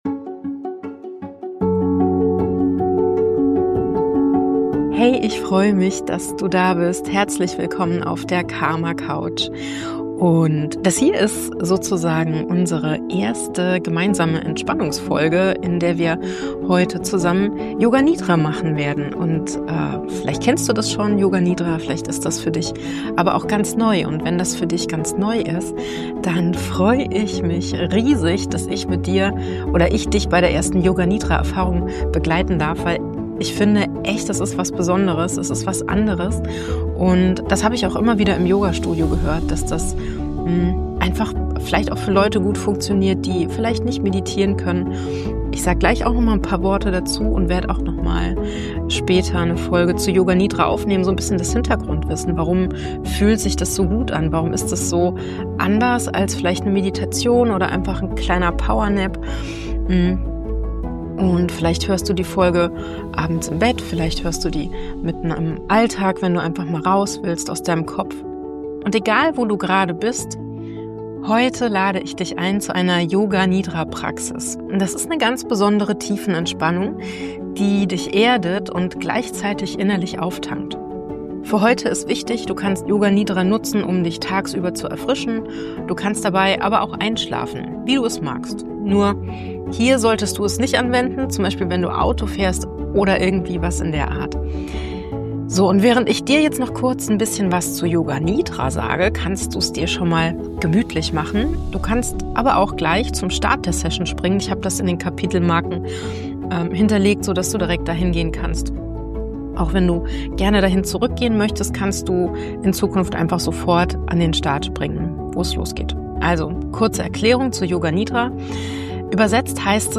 Und für den Start habe ich etwas Schönes ausgesucht: Yoga Nidra ist eine ziemlich besondere Art der Tiefenentspannung, die dich erdet und erfrischt. Über eine bewusst monoton gesprochene Anleitung begibst du dich auf eine Reise durch deinen Körper, kreierst Bilder und innere Wahrnehmungszustände und atmest bewusst und entspannt - wenn du es schaffst, wach zu bleiben.